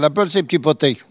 Elle crie pour appeler les canetons